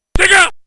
new voice now says "SEGA!", this was kept for the final game.
mhp sega.mp3